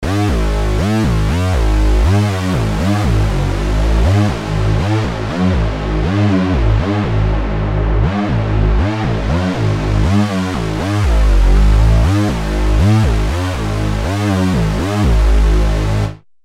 RS6-Industrial-Unison.mp3